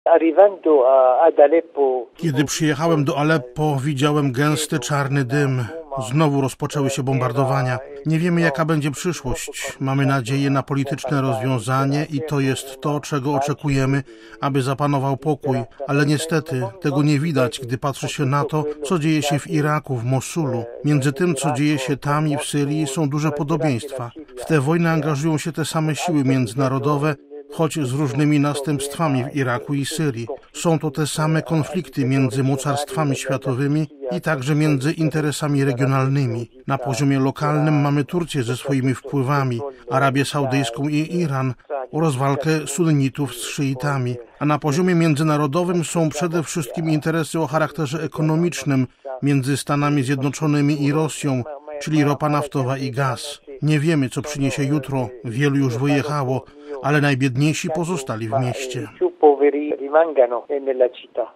Trwa ofensywa wojskowa na Aleppo w Syrii i Mosul w Iraku. Chaldejski biskup Antoine Audo mówił w rozmowie z Radiem Watykańskim o ciągłych atakach na to miasto i bombardowaniach, które nie oszczędzają nikogo.